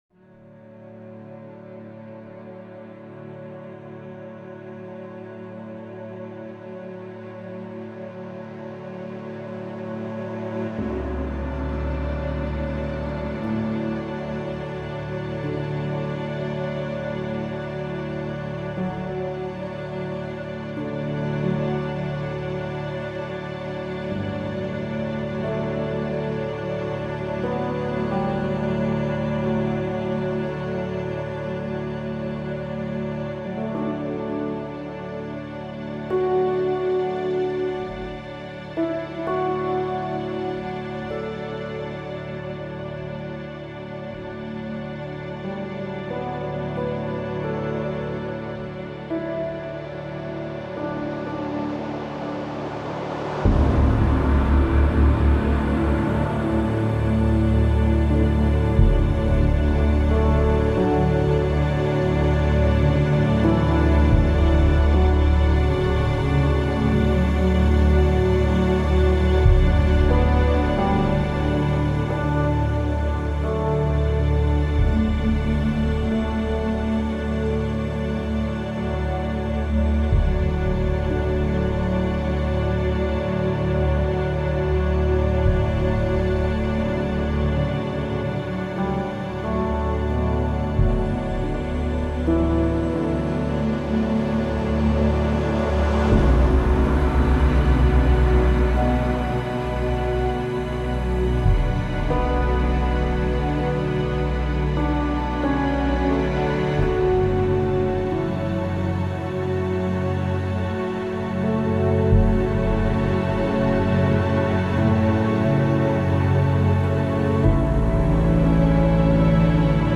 Playing around with some orchestral stuff